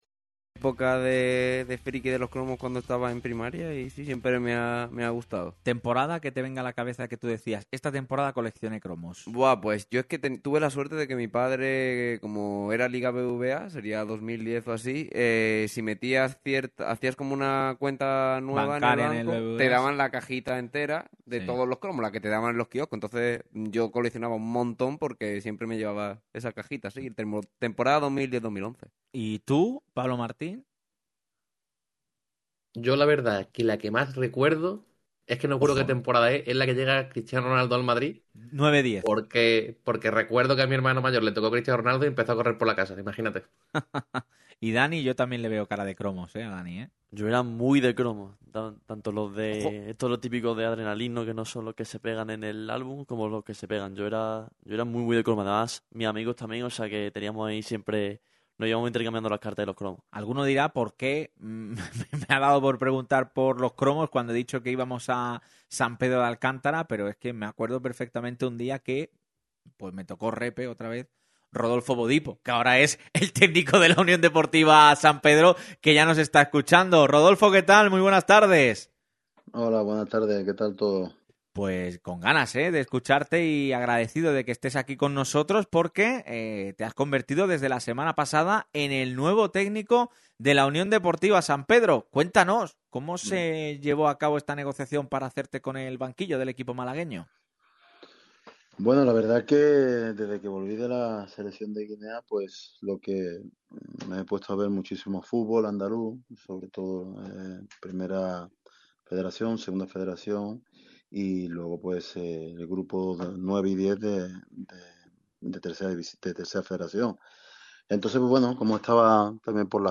Entrevista completa de Rodolfo Bodipo, nuevo entrenador de la UD San Pedro, en Radio MARCA Málaga